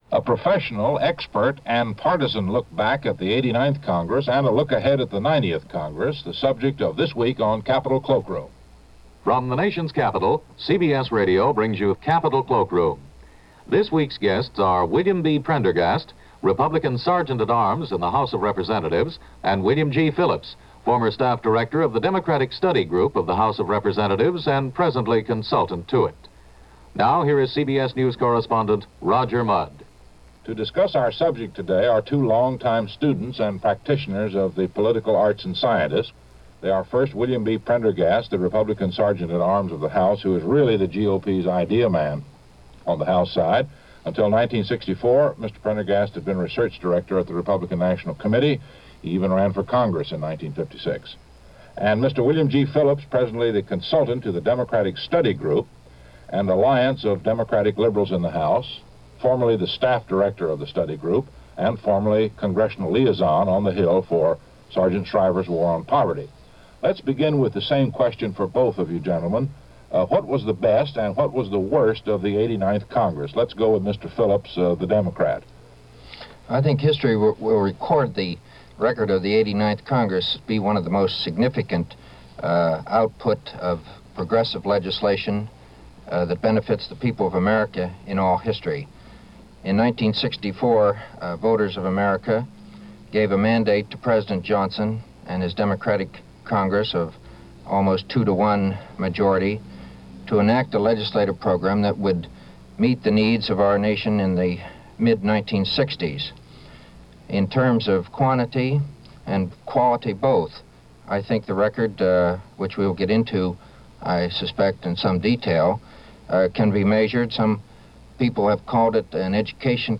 They are asked what they felt was the best and what was the worst of the 89th Congress.